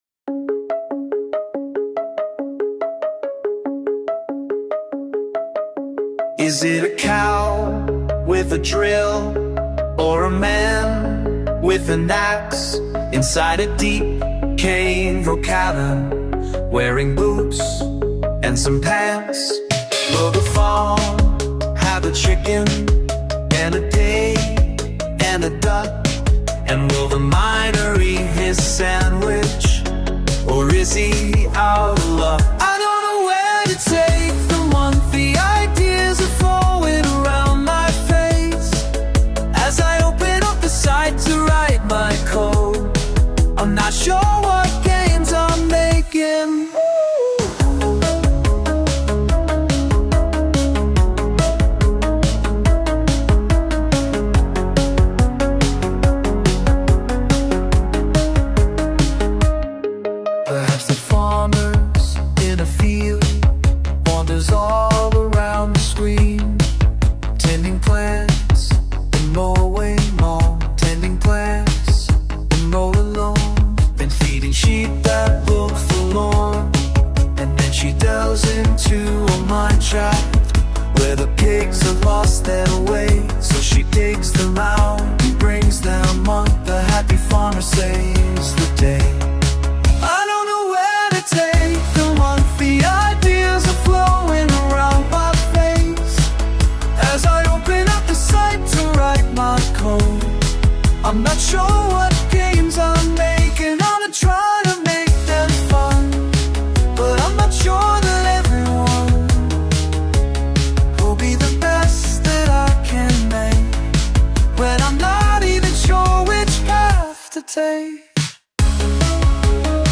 It's not "too" bad, now, and .. sure is one hell of an improvement.. but it still has that squelchiness to the audio. It frequently seems to drop a fraction of a second and that hurts the overall quality, IMO.